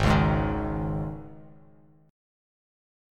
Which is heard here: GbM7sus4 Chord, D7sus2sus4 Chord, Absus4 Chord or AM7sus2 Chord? Absus4 Chord